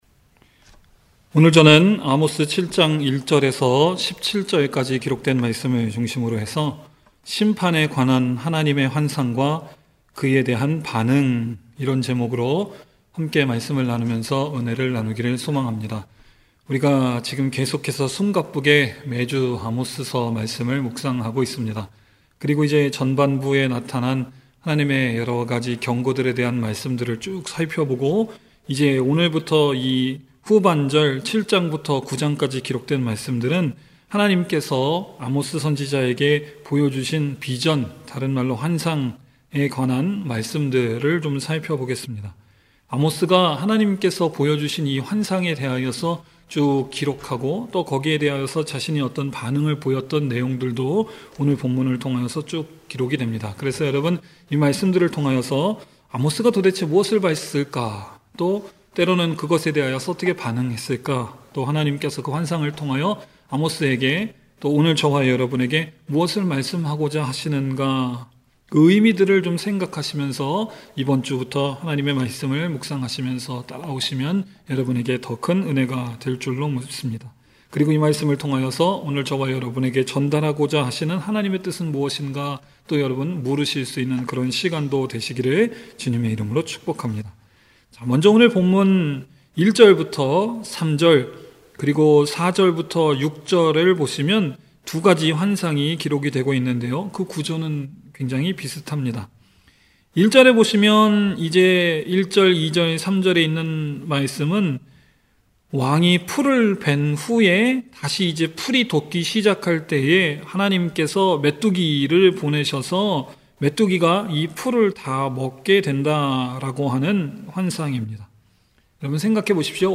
주일설교 | 세인트루이스 반석침례교회(Bansuk Baptist Church of St. Louis)